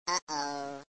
失败啊哦.mp3